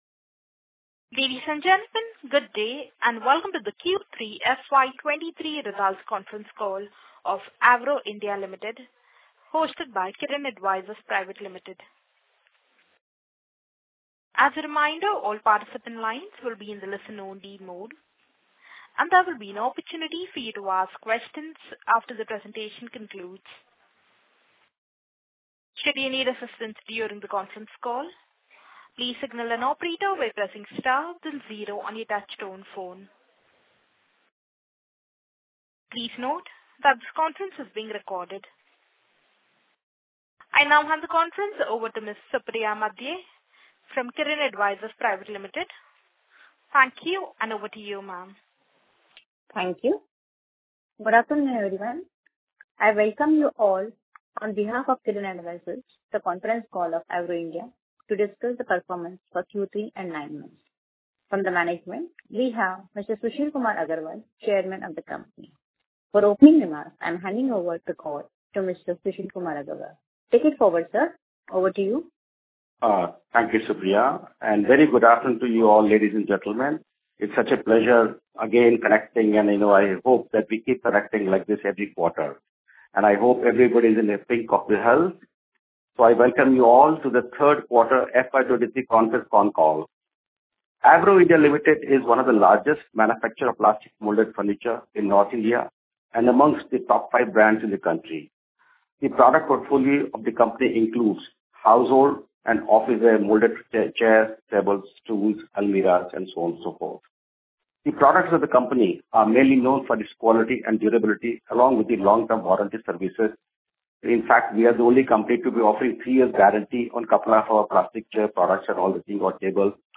Investor Concall Audio Recording 14.08.2023